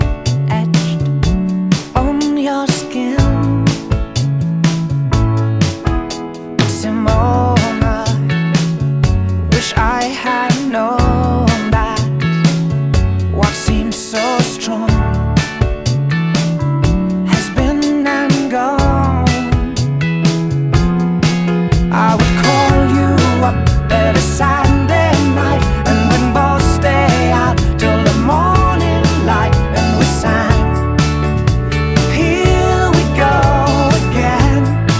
Pop Rock Adult Contemporary Adult Alternative
Жанр: Поп музыка / Рок / Альтернатива